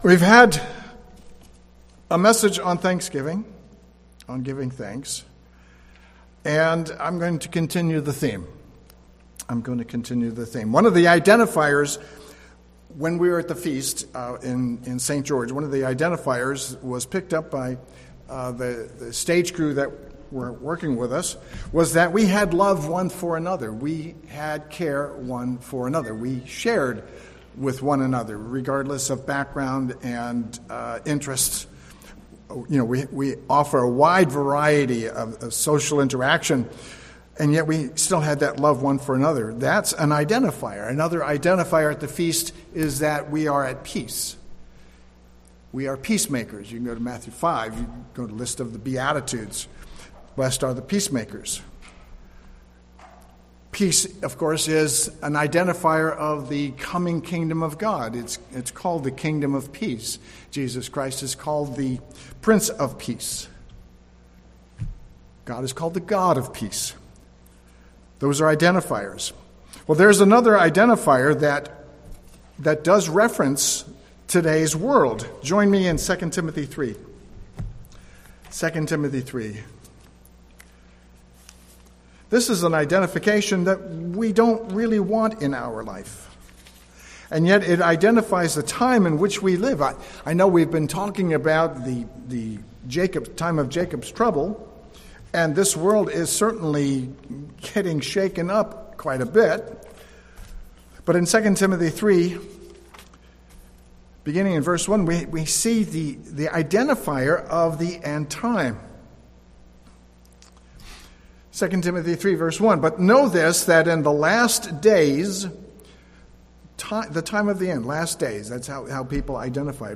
Be Thankful In Everything | United Church of God